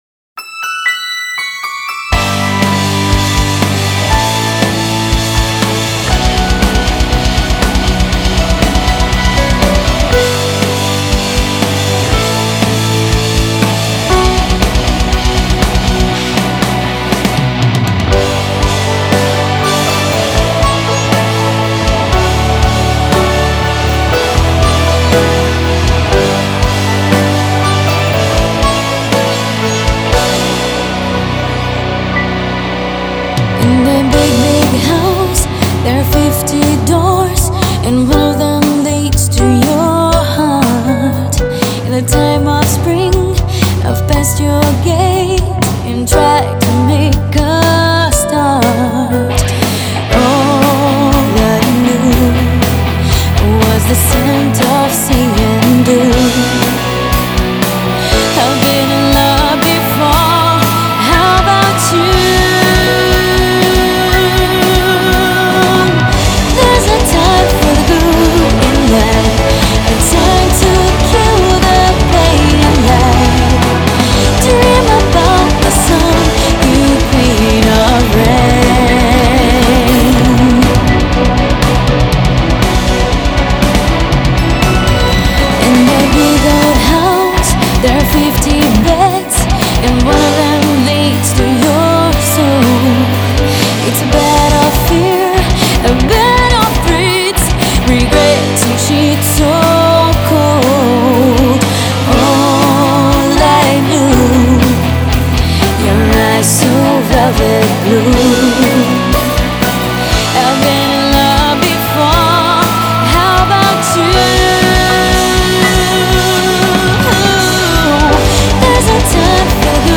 • Категория:Метал каверы